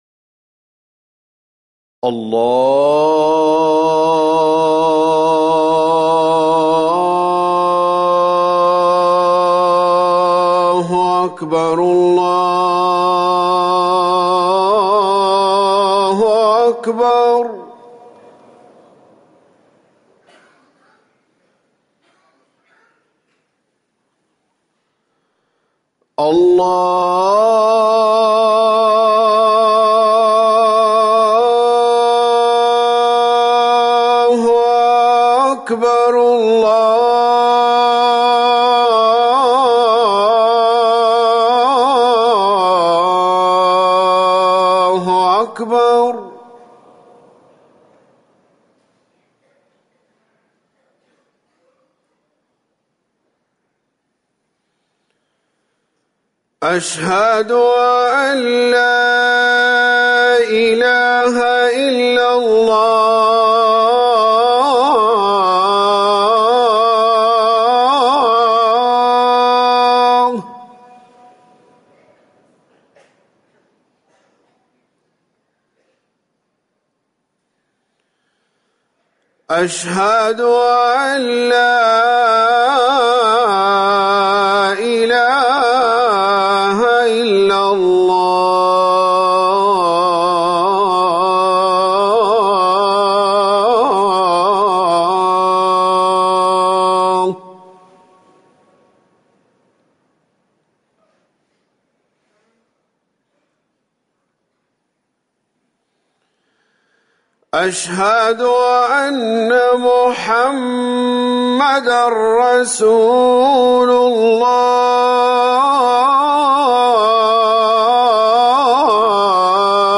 أذان الفجر الثاني - الموقع الرسمي لرئاسة الشؤون الدينية بالمسجد النبوي والمسجد الحرام
تاريخ النشر ١٨ صفر ١٤٤١ هـ المكان: المسجد النبوي الشيخ